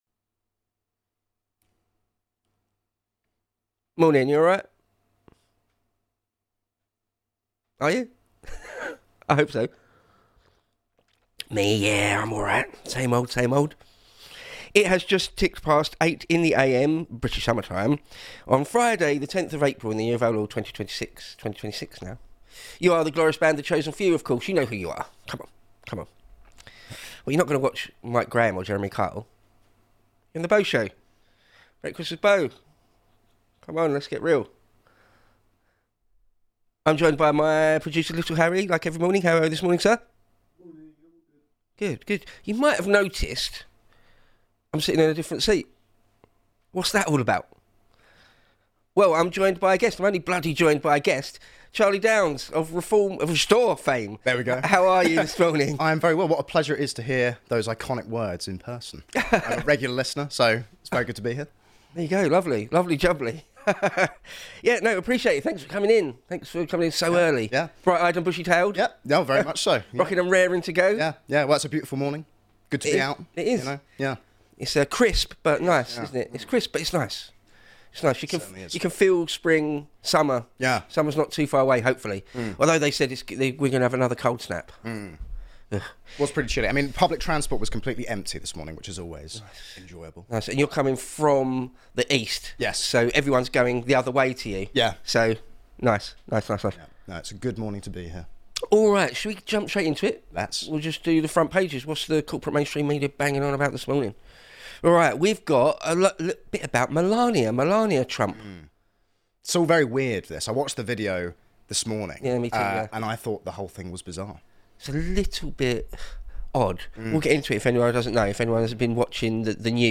Live 8-9am GMT on weekdays.